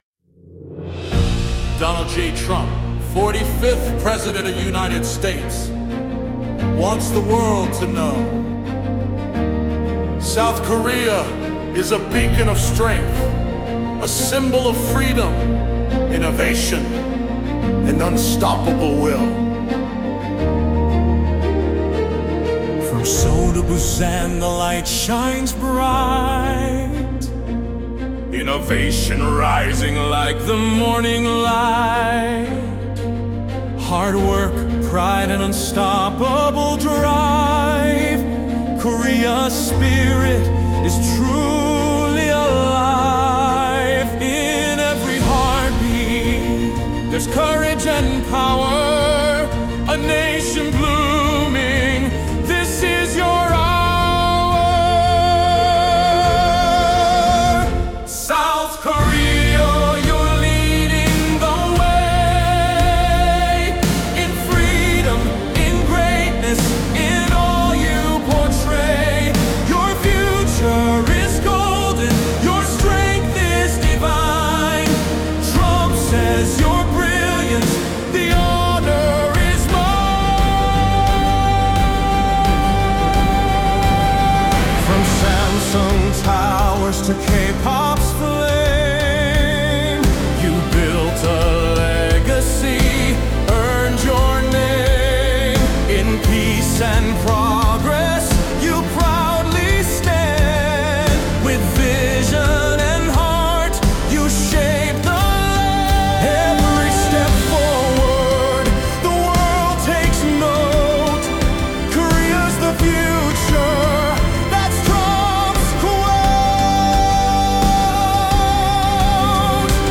ChatGPT, SUNO